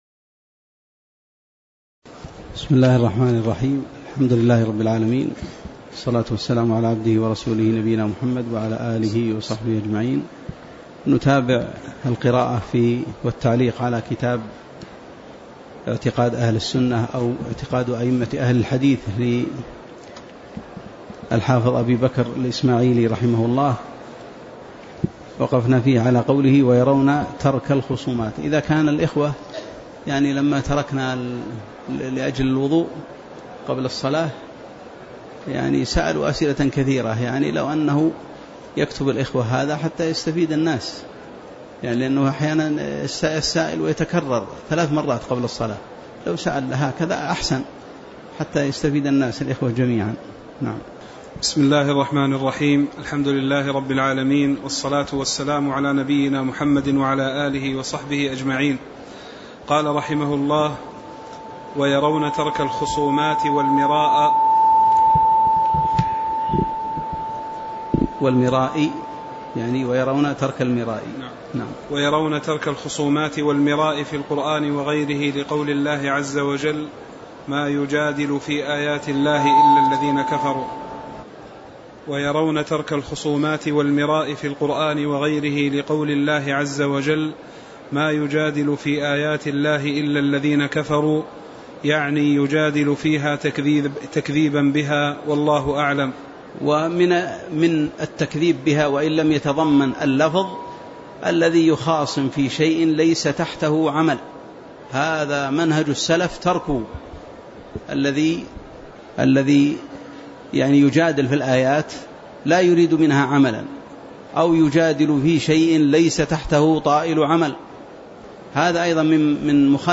تاريخ النشر ١٦ جمادى الآخرة ١٤٣٨ هـ المكان: المسجد النبوي الشيخ